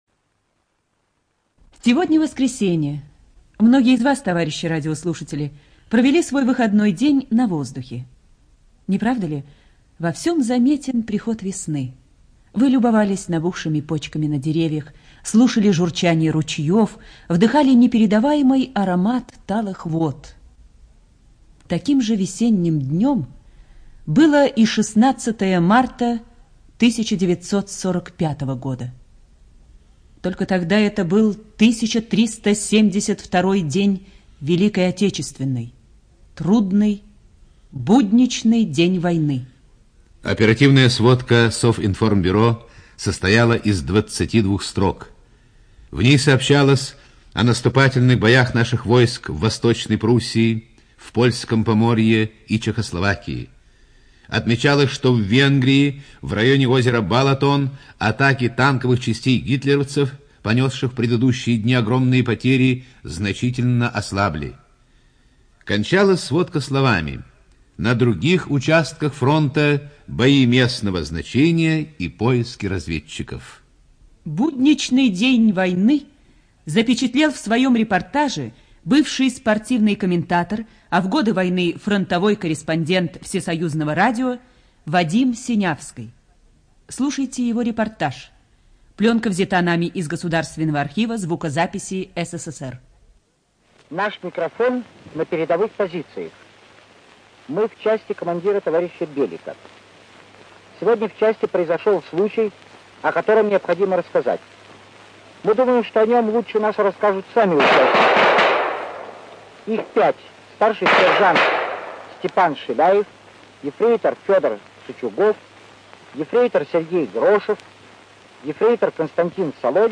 ЖанрДокументальные фонограммы
Студия звукозаписиРадио Маяк